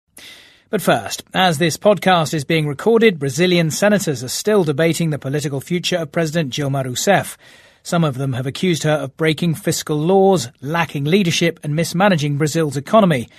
【英音模仿秀】巴西总统弹劾案近况 听力文件下载—在线英语听力室